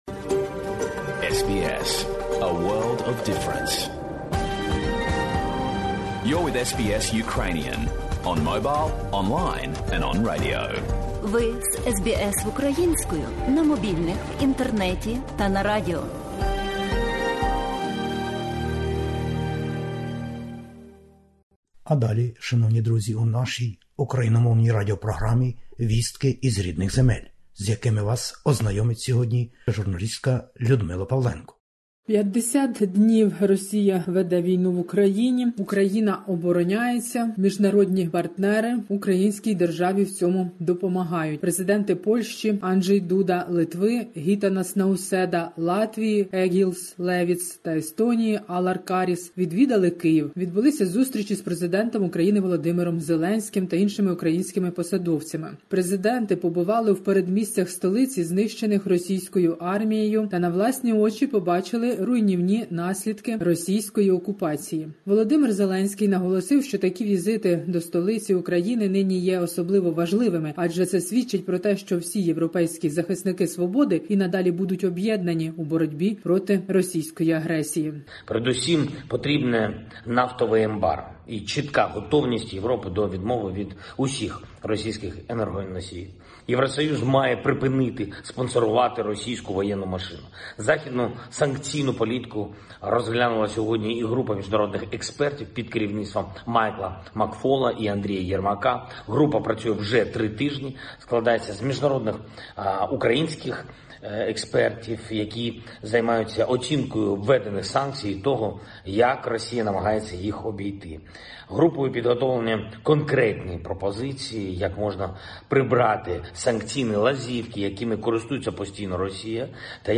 Добірка новин із воюючої України та Звернення Президента В. Зеленського. 50 днів широкомасштабної війни в Україні через вторгнення російських збройних сил. Російський крейсер «Москва» затонув, повідомили 14 квітня російські державні аґенції новин із посиланням на Міноборони Росії.